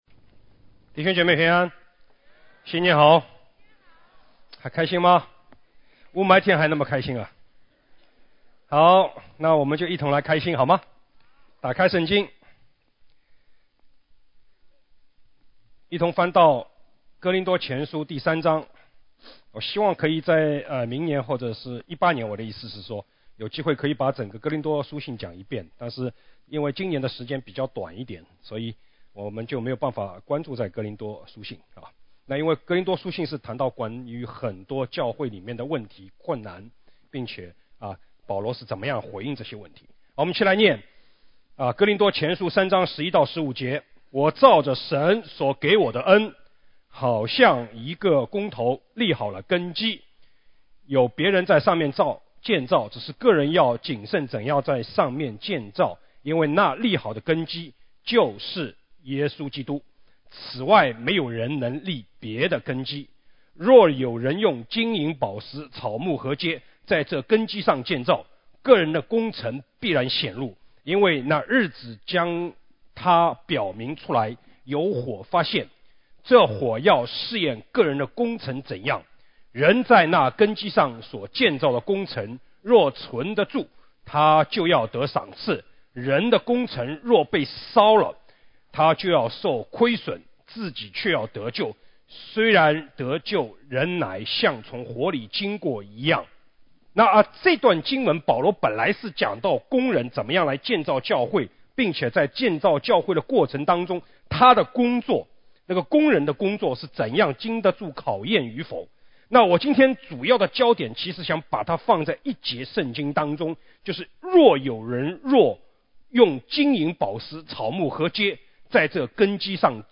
主日证道 | 教会的建造